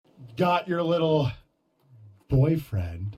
got your little boyfriend Meme Sound Effect
This sound is perfect for adding humor, surprise, or dramatic timing to your content.